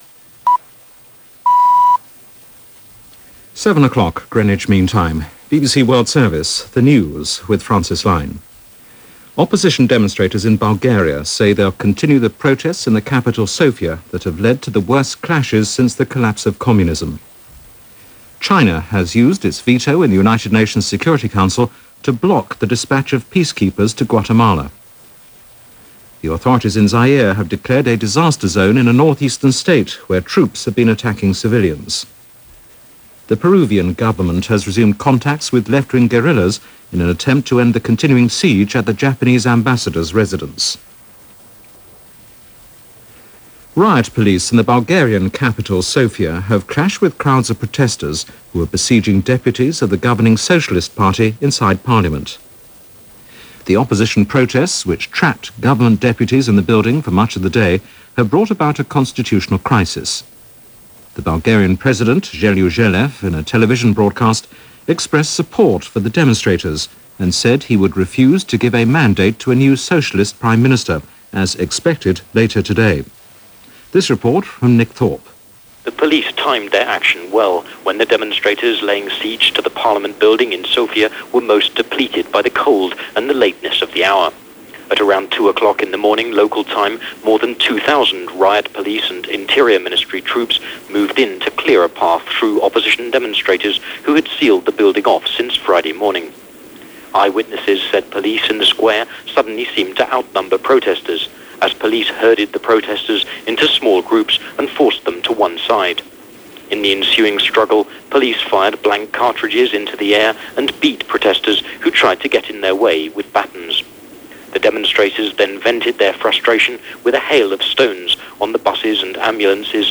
January 10, 1997 – BBC World Service News – BBC –